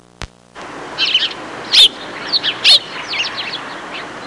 Blackbird Sound Effect
Download a high-quality blackbird sound effect.
blackbird-4.mp3